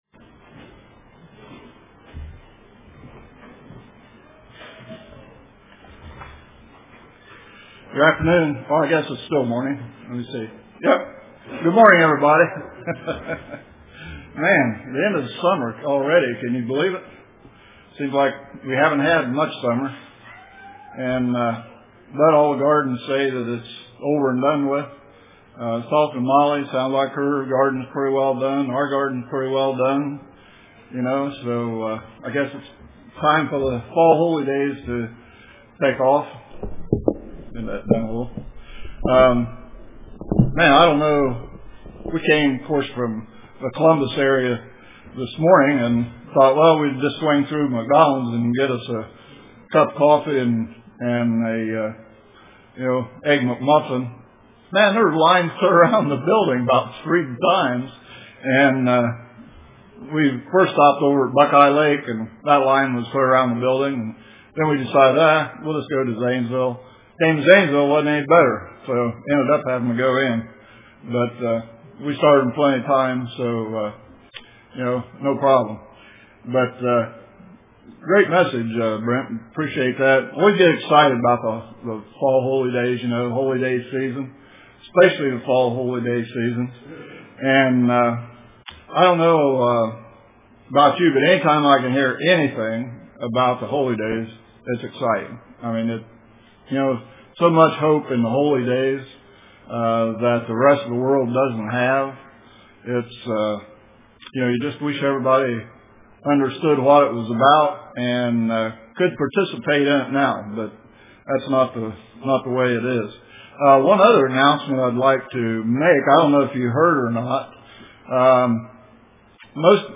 Greatest Events in World History UCG Sermon Studying the bible?